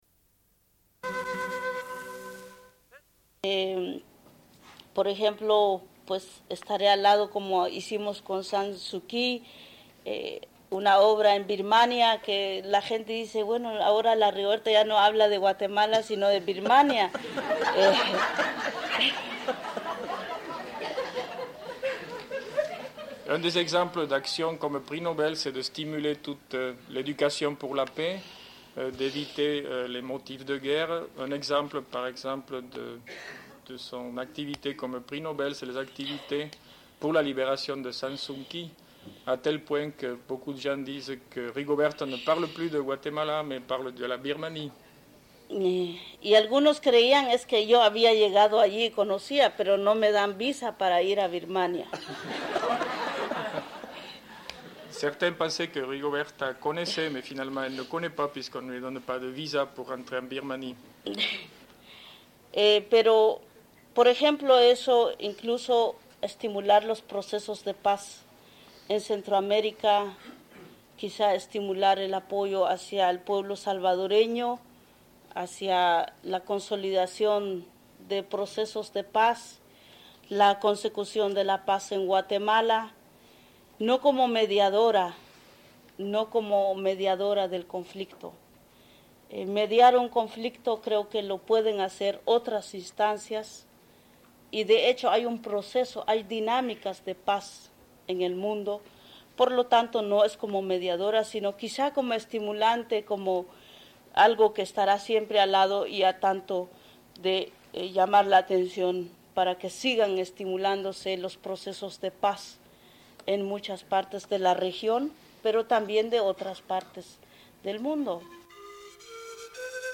Une cassette audio, face B29:09